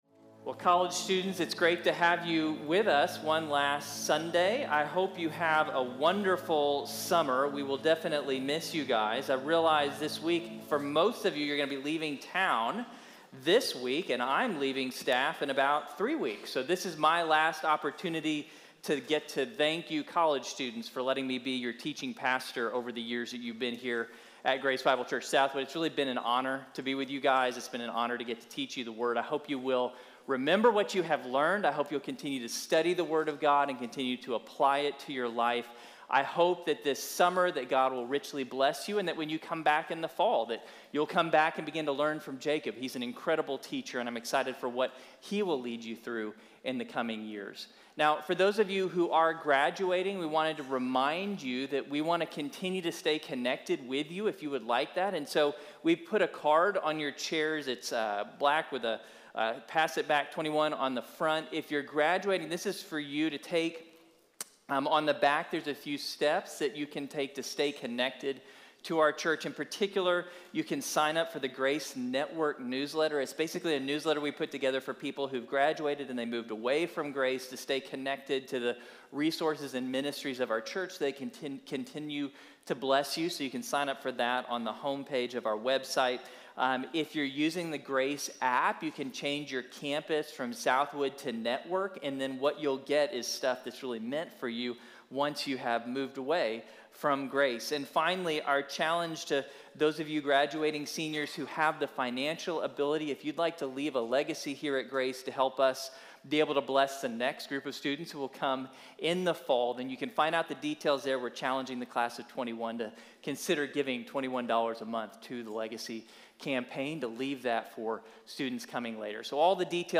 Abraham’s Faith | Sermon | Grace Bible Church